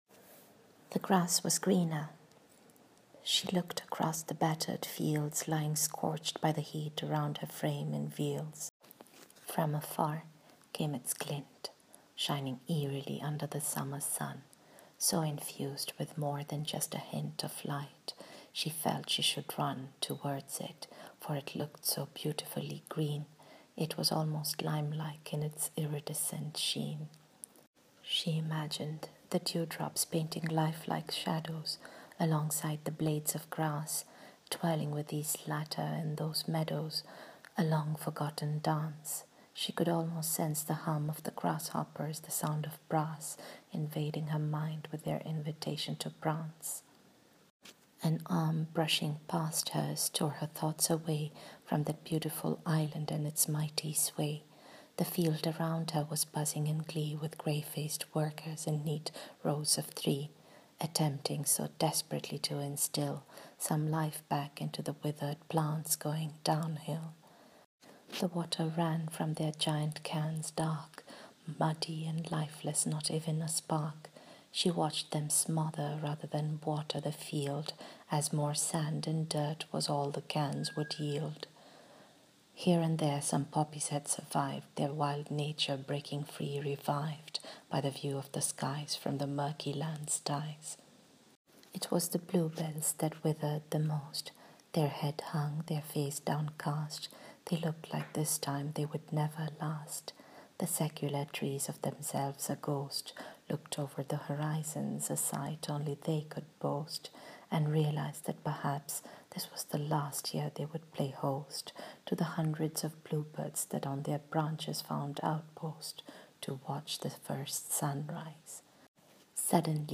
Reading of the short story: